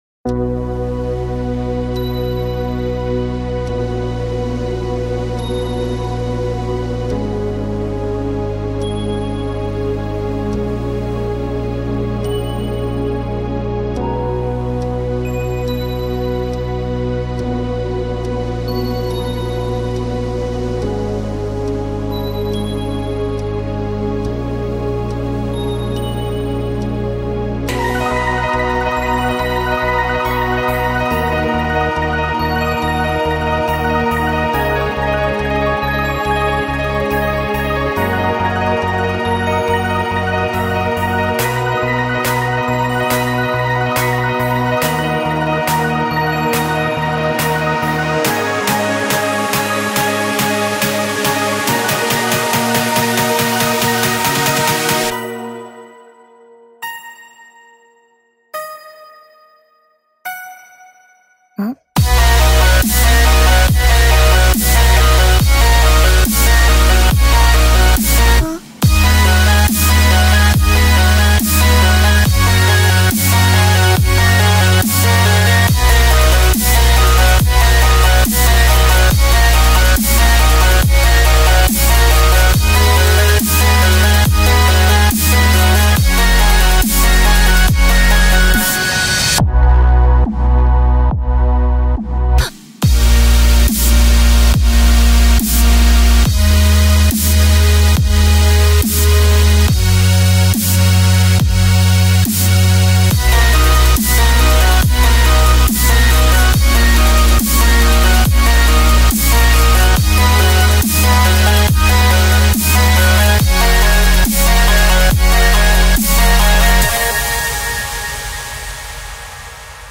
норвежской певицы